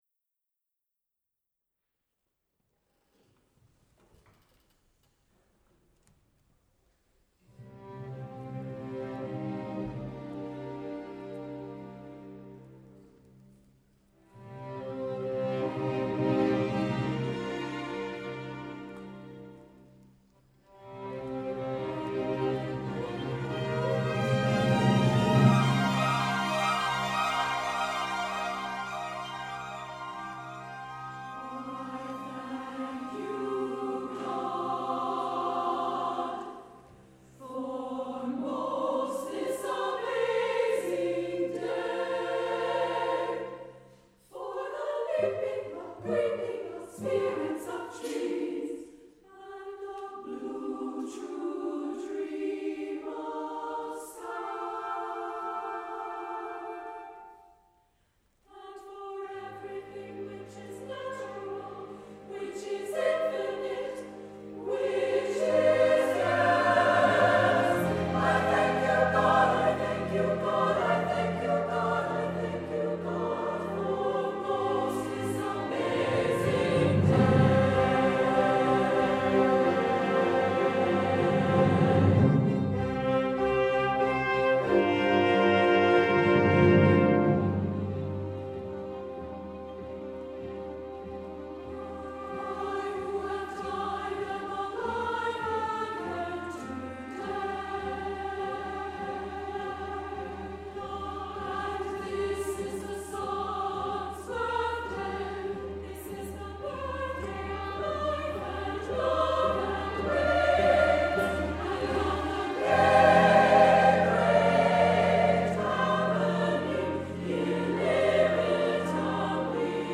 SSA and orchestra